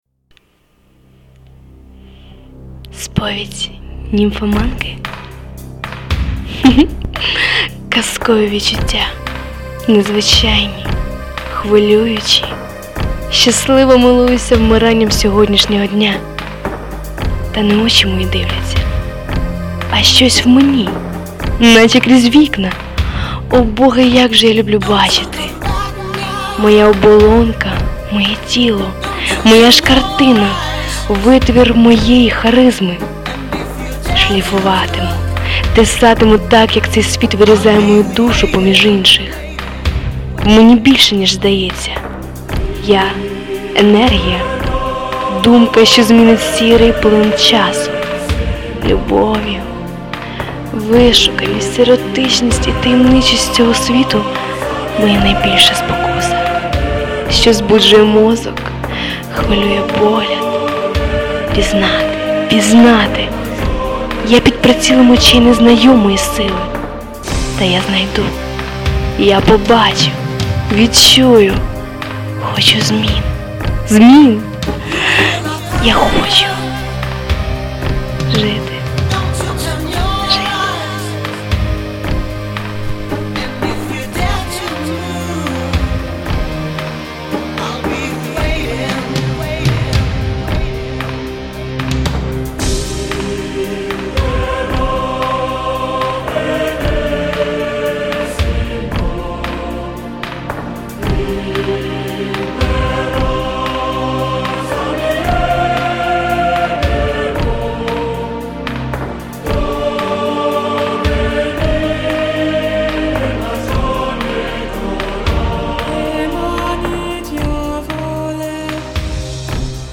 Приємний голос....а вірш дуже чарівний)))) friends